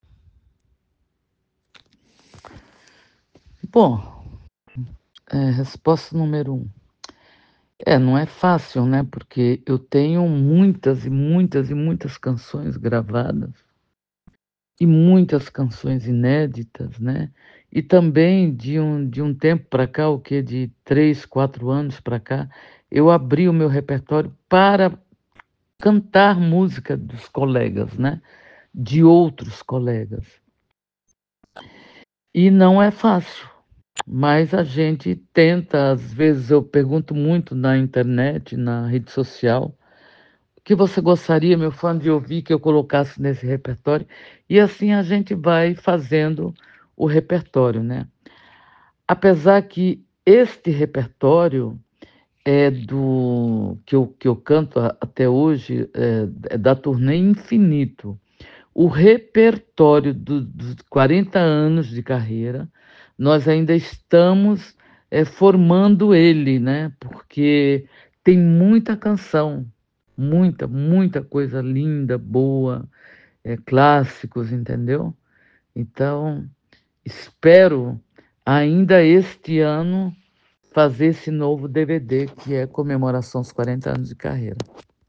“Não abro mão da poesia, da história, boa música e da arte” diz Roberta Miranda em entrevista exclusiva para o Curitiba Cult; cantora faz show na cidade em agosto
E ela ainda deixou um recado para nós: